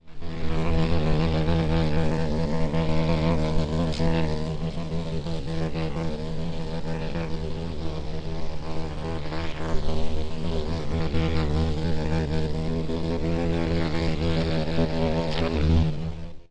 На этой странице собраны разнообразные звуки майского жука: от характерного жужжания до шума крыльев в полете.
Шум полета большого майского жука